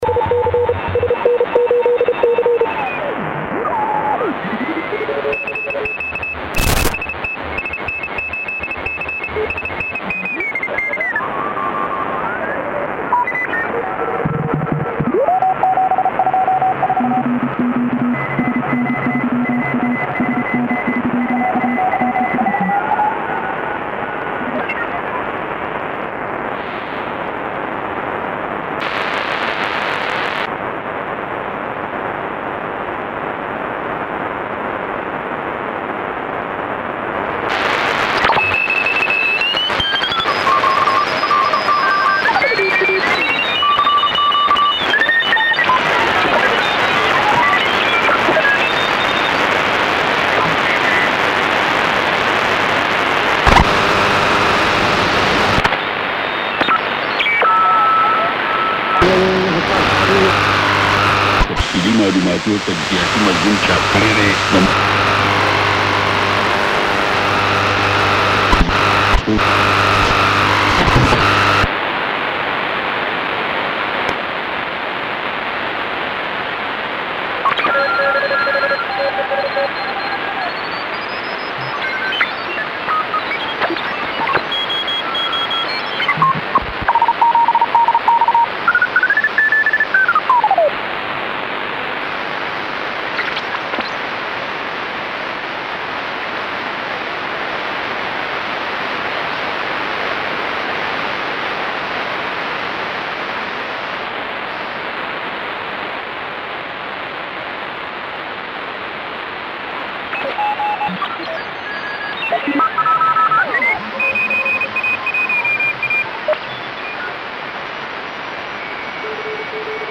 Шуммный эфир г.Дубна день.